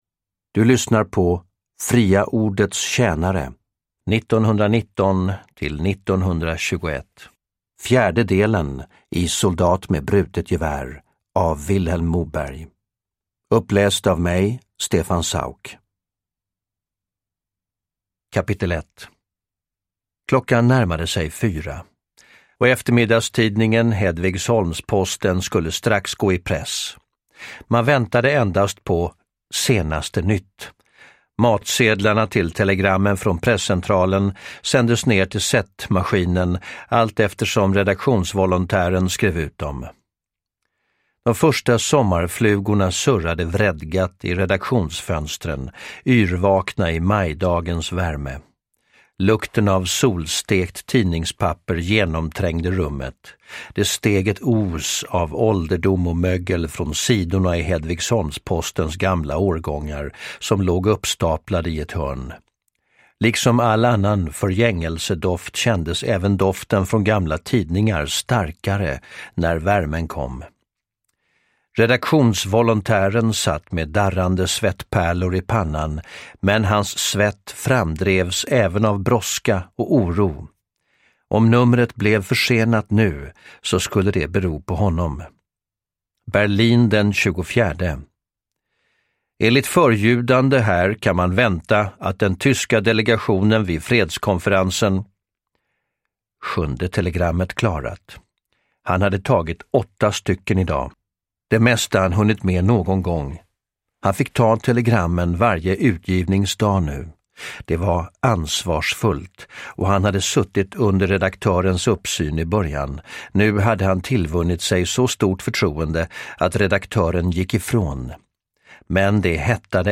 Uppläsare: Stefan Sauk